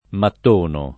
mattono [ matt 1 no ]